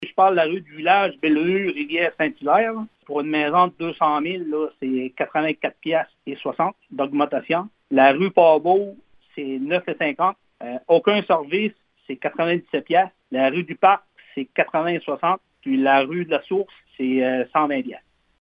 Le maire, Gino Cyr, explique la différence suite à certains investissements effectués dans sa ville.